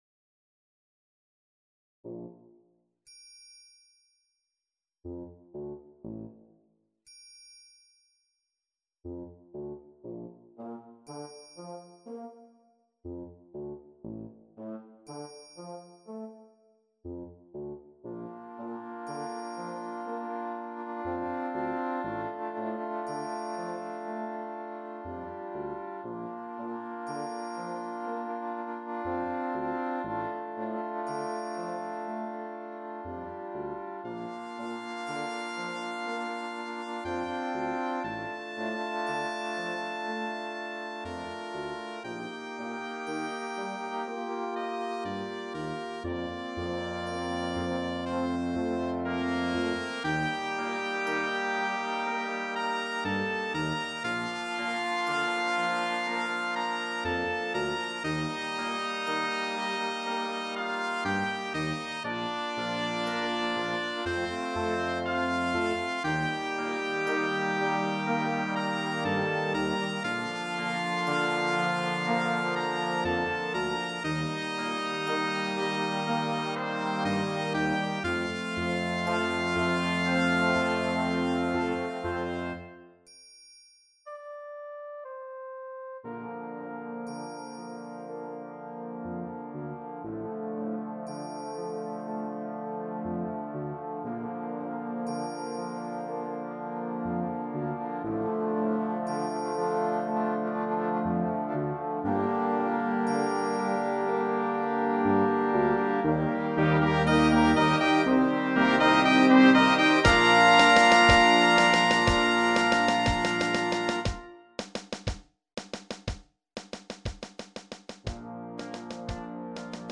Brass Band
Computer Generated